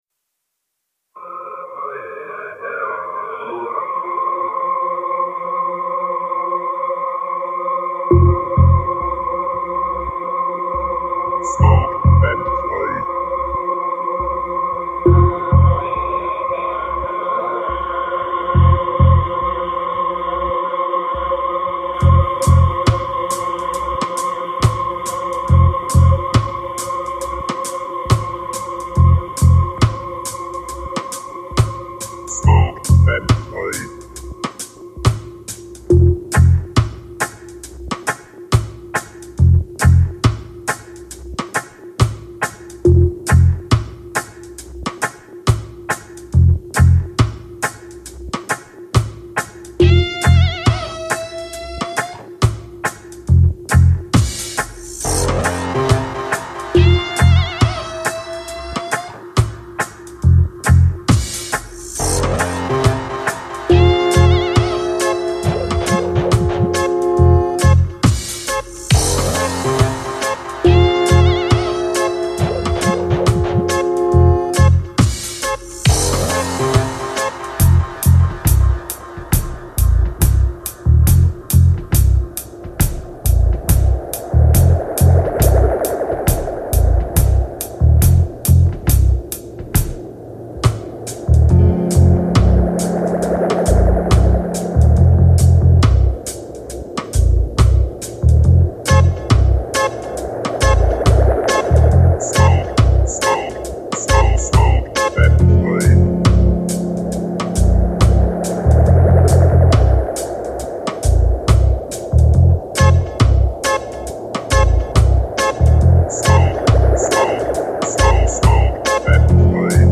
smoke and fly (dub)
Прошу оценить даб "Кури и лети" Длина: 341 сек, 112 кбит/c